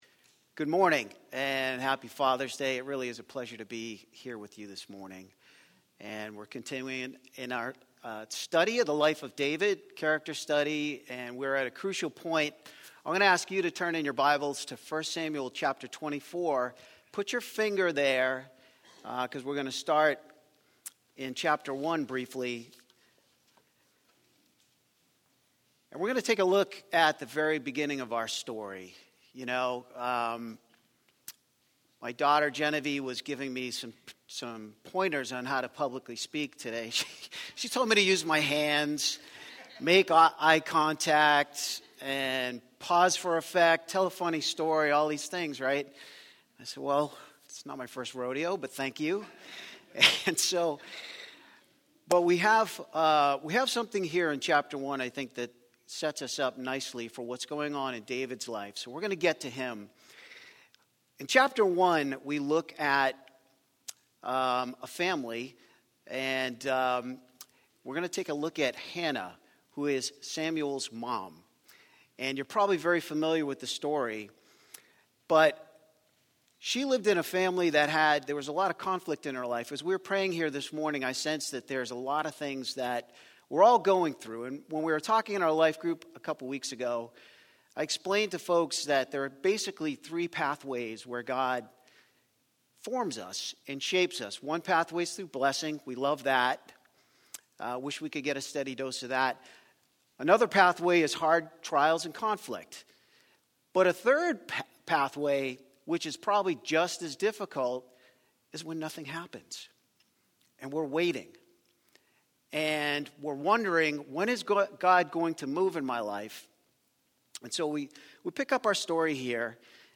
ล่าสุดคือ Interview - The Fact & Fiction of Crime in SA with Deon Meyer.
1 Interview - The Fact & Fiction of Crime in SA with Deon Meyer 46:17 Play Pause 8h ago 46:17 Play Pause เล่นในภายหลัง เล่นในภายหลัง ลิสต์ ถูกใจ ที่ถูกใจแล้ว 46:17 In today's episode I have the incredible pleasure of chatting with one of the world's most successful crime fiction writers, Deon Meyer. Meyer's books have enthralled and captivated readers in South Africa for decades and our conversation reveals how that often entails getting closer to real crime than one might think.